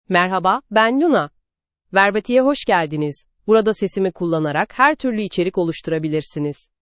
Luna — Female Turkish (Turkey) AI Voice | TTS, Voice Cloning & Video | Verbatik AI
Luna is a female AI voice for Turkish (Turkey).
Voice sample
Listen to Luna's female Turkish voice.
Luna delivers clear pronunciation with authentic Turkey Turkish intonation, making your content sound professionally produced.